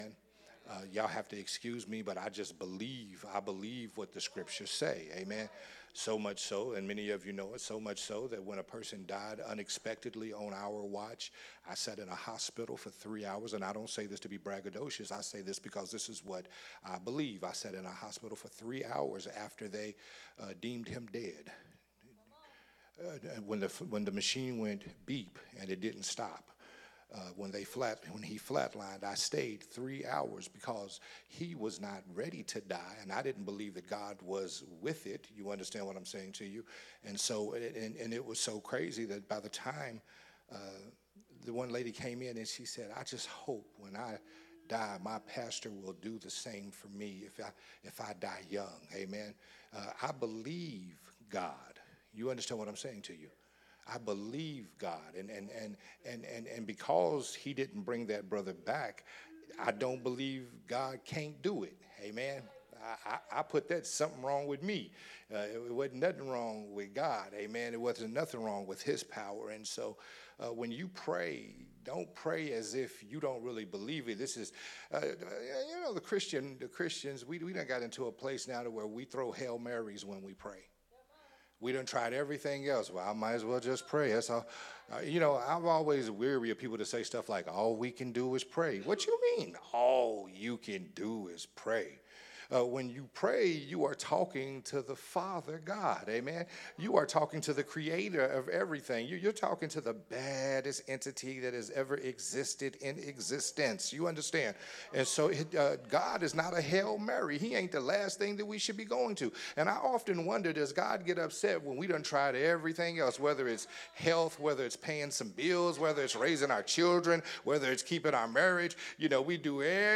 Sunday Morning Worship Service
recorded at Unity Worship Center